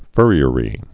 (fûrē-ə-rē)